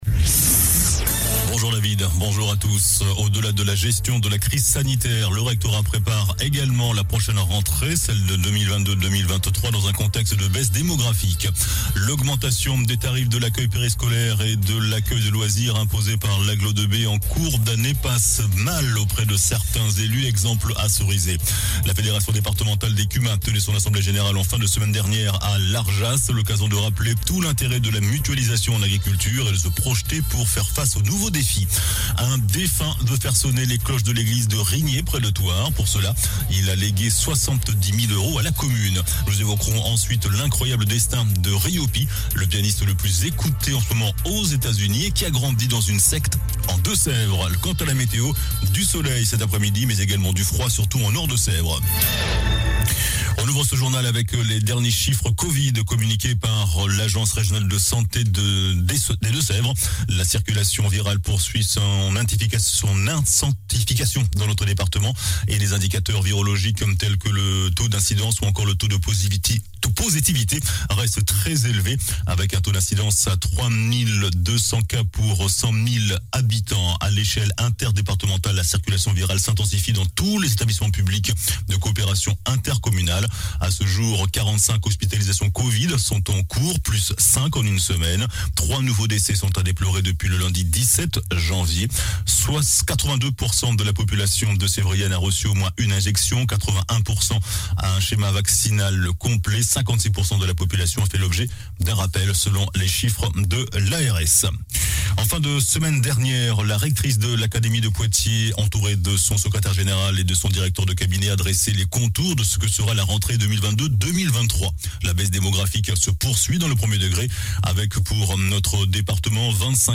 Journal du jeudi 25 novembre (midi)